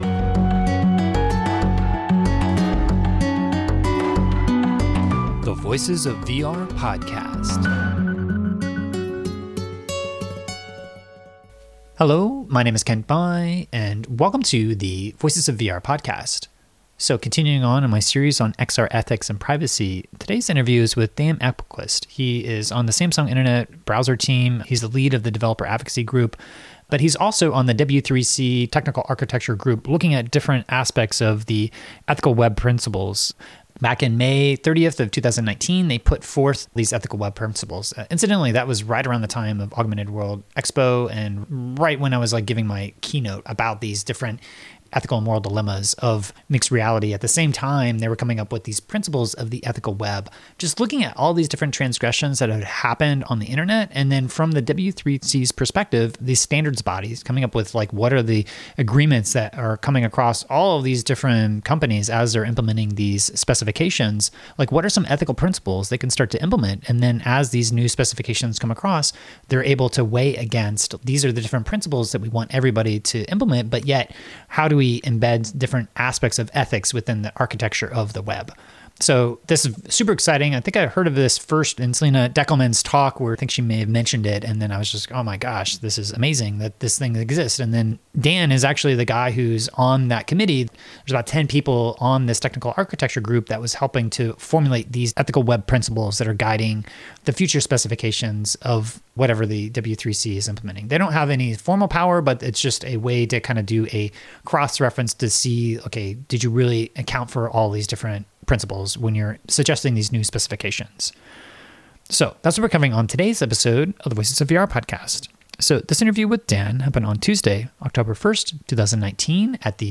today's interview